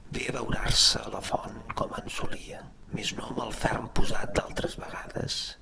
speech-male_hprModel_residual
Category 🗣 Voices
catalan hpr hprModel male residual sms sms-tools speech sound effect free sound royalty free Voices